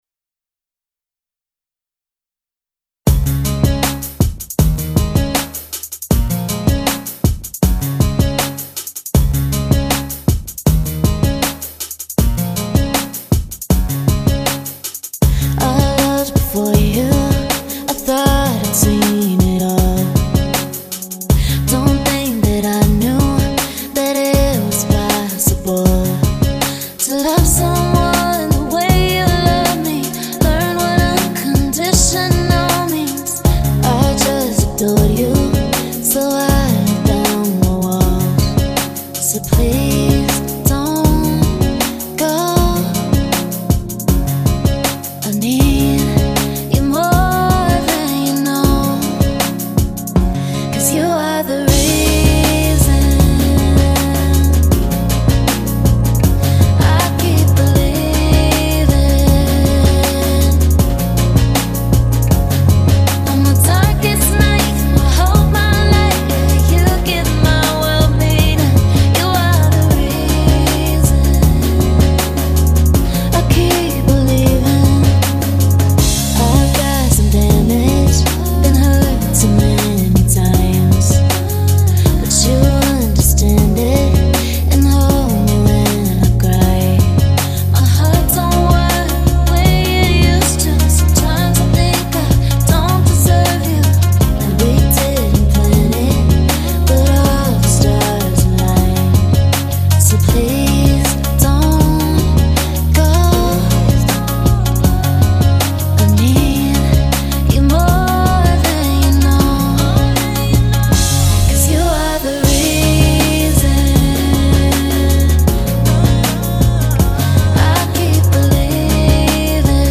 Song title is "The Reason" Instruments from Native Instruments Mixed with Waves Plugins Critiques appreciated, thanks.